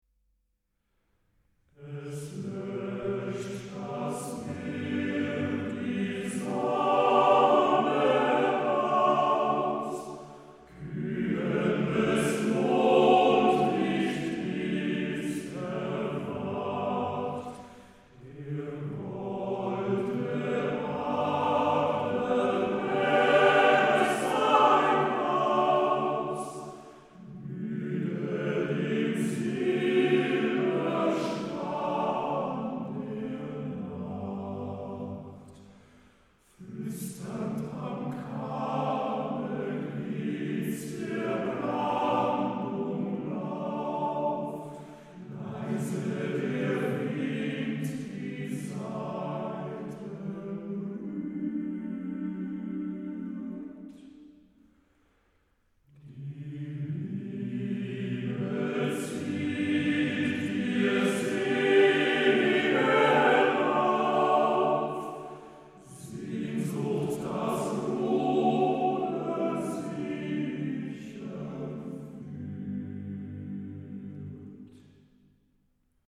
DER MÄNNERCHOR
Männerchöre der Romantik